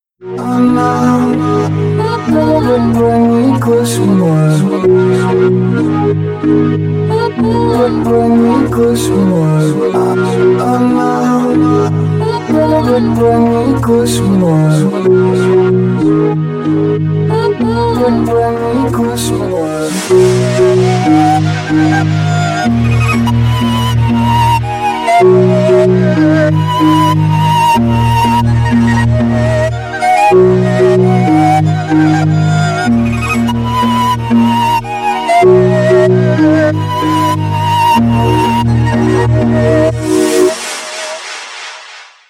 • Качество: 320, Stereo
Electropop
Атмосферное музло